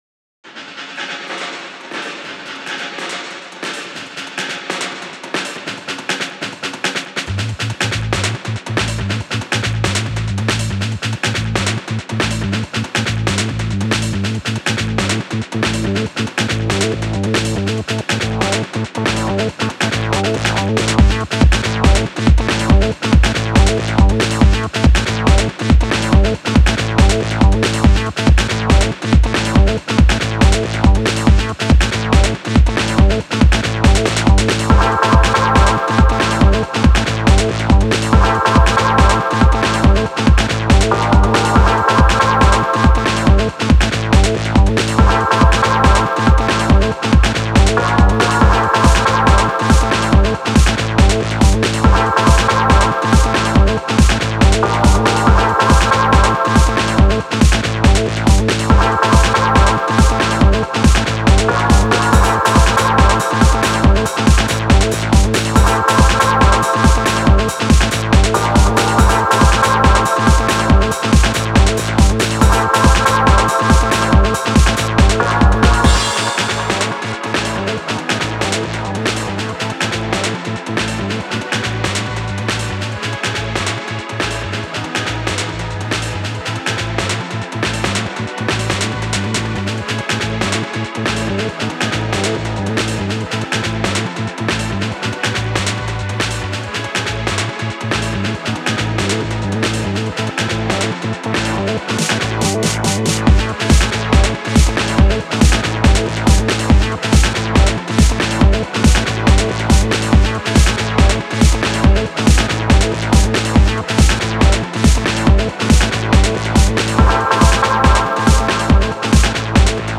A Techno Track with a 90s Racing Vibe.